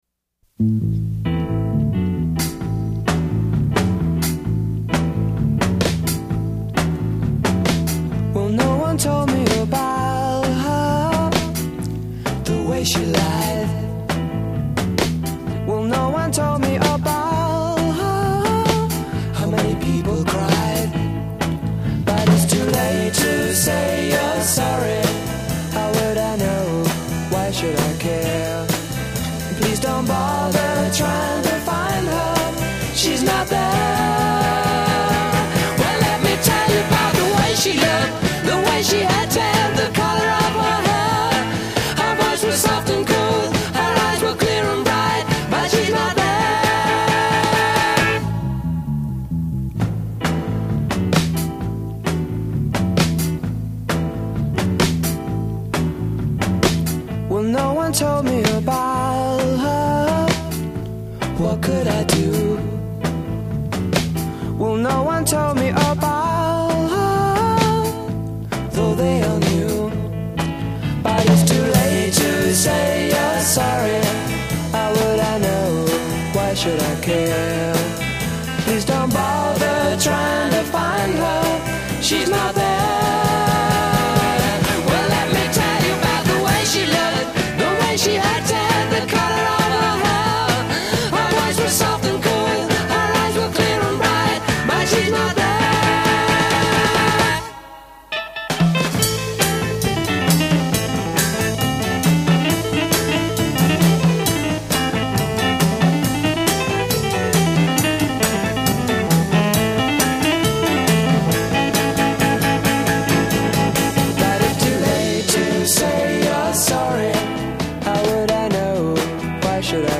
Intro   0:00 4 Bass begins, followed by piano and drums.
(The bass may be doubled by a keyboard instrument.)
Second piano appears on backing track (left channel).   b
Verse   :   Piano solo against piano, guitar, bass, drums.